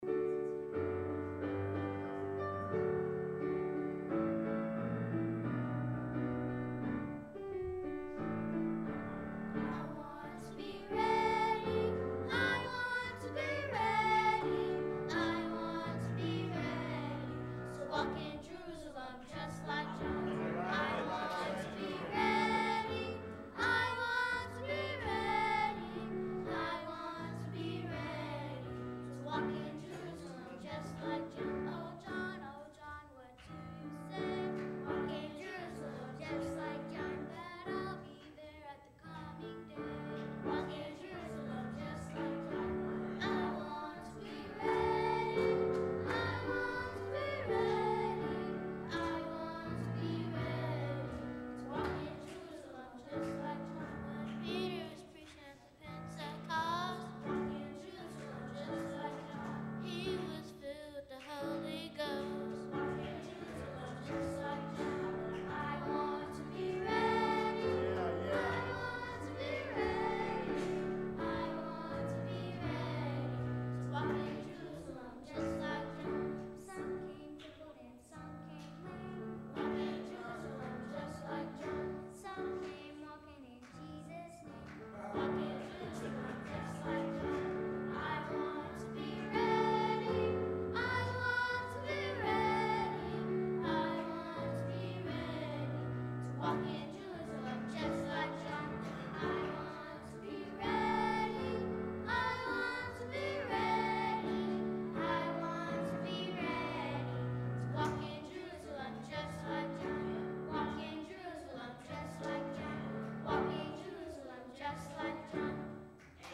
Morning Worship Service
Youth Choir
Chere Here To Hear Youth Choir